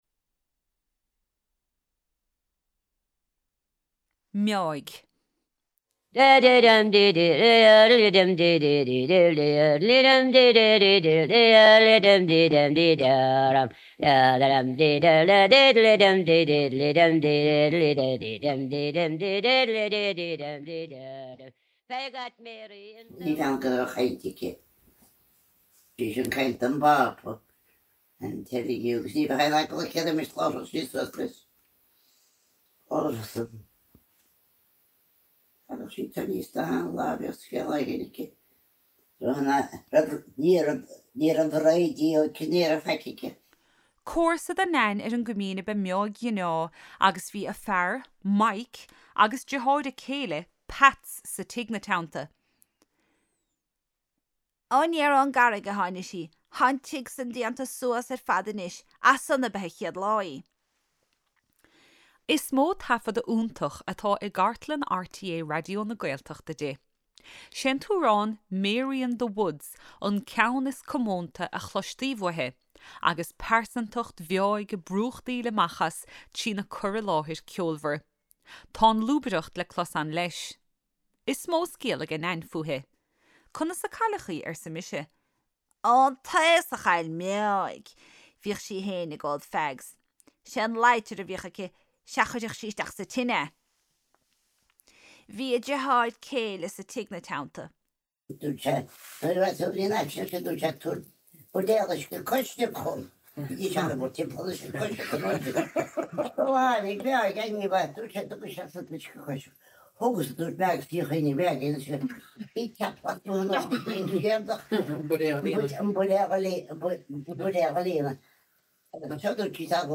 Nain (closleabhar)